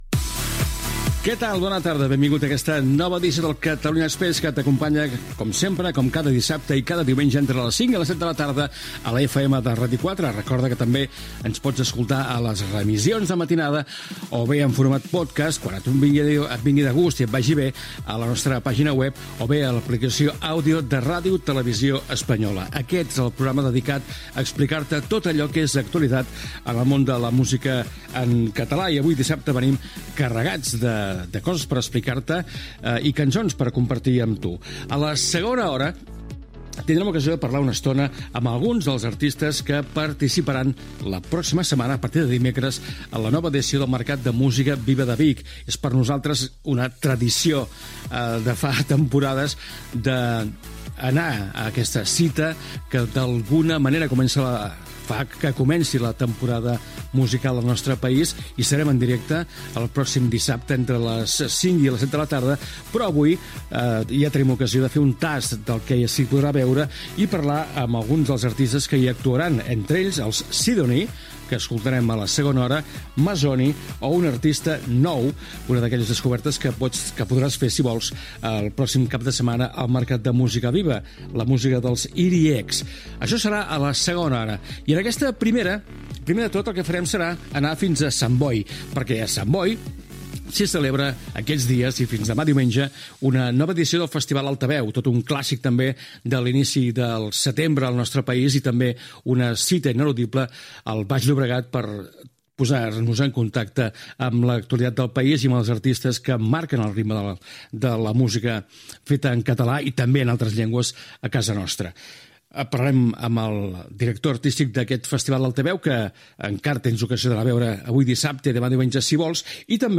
Indicatiu de la ràdio, careta del programa.
Salutació i sumari de les dues hores, tema musical.